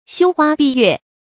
羞花閉月 注音： ㄒㄧㄨ ㄏㄨㄚ ㄅㄧˋ ㄩㄝˋ 讀音讀法： 意思解釋： 形容女子貌美，使花亦羞愧，月亦隱藏。